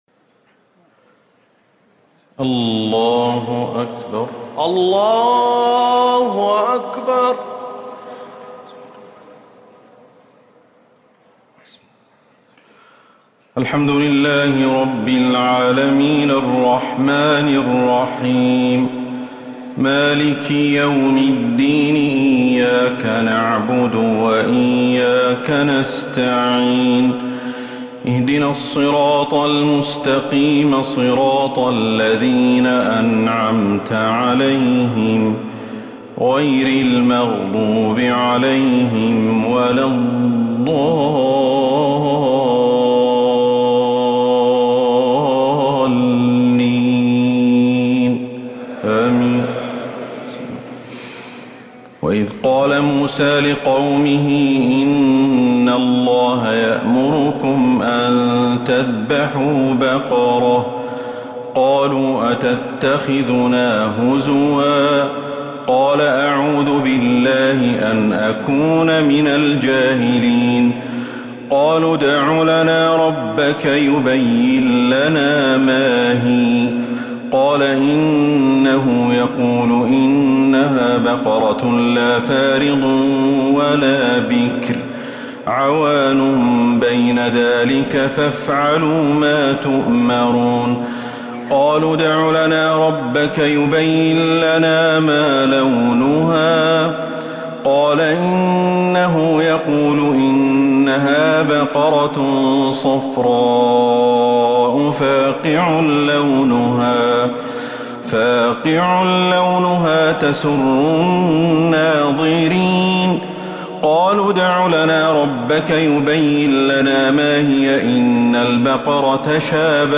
تراويح ليلة 1 رمضان 1444هـ من سورة البقرة {67-105} Taraweeh 1st night Ramadan 1444H > تراويح الحرم النبوي عام 1444 🕌 > التراويح - تلاوات الحرمين